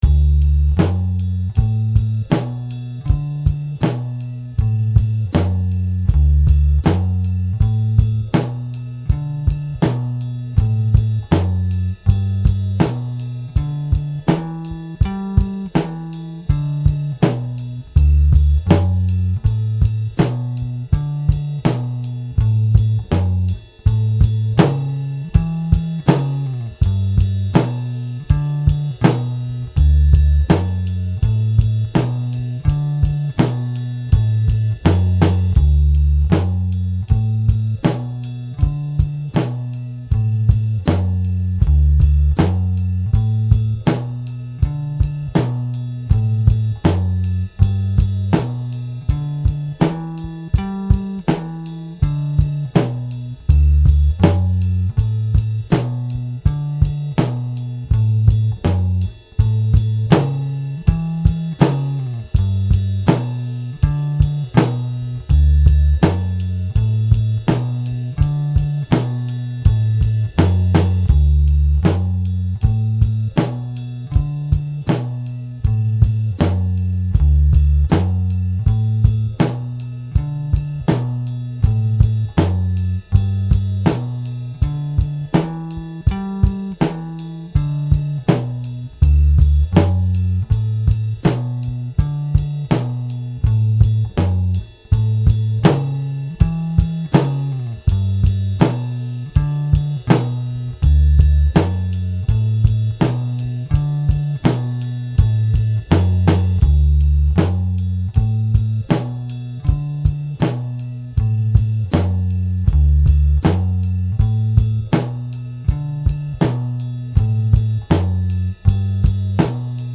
Today we’ll be playing in the key of D.